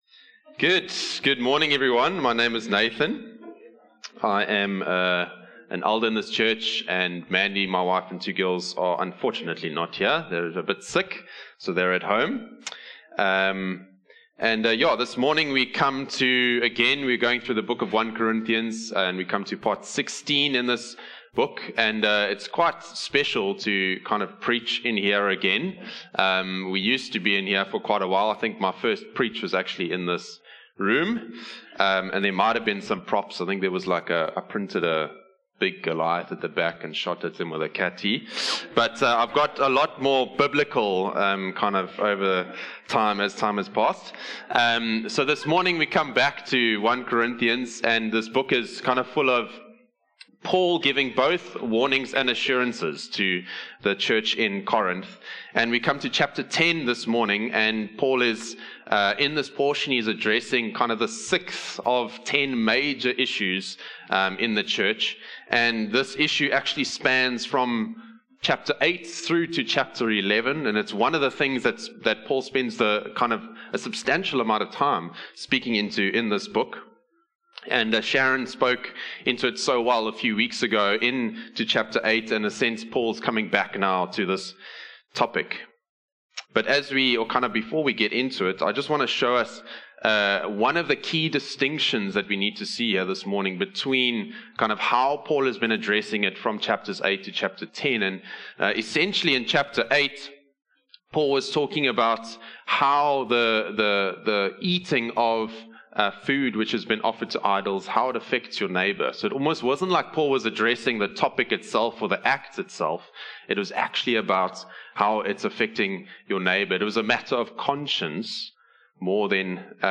One-Hope-Sermon-7-September-2025.mp3